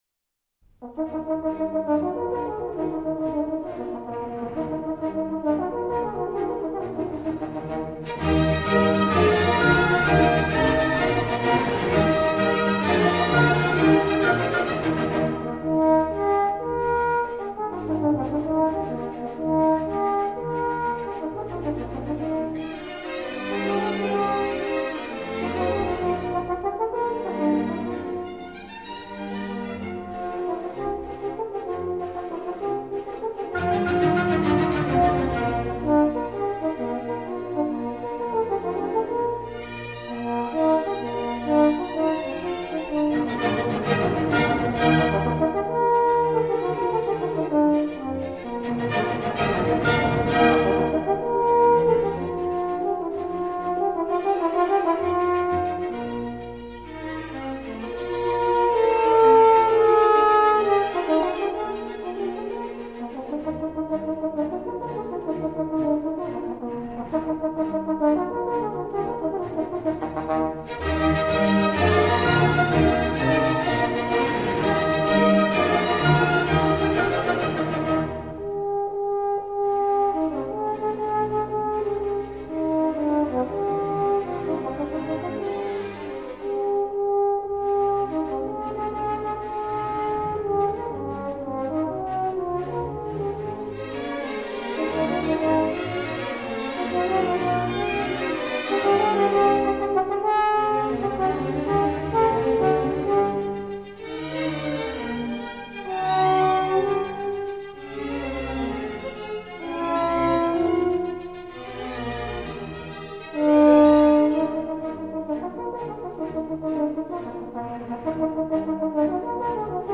F06-11 Horn Concerto No. 4 in E flat maj | Miles Christi